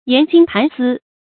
研精覃思 yán jīng tán sī
研精覃思发音
成语注音 ㄧㄢˊ ㄐㄧㄥ ㄊㄢˊ ㄙㄧ